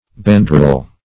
Bandrol \Band"rol\, n.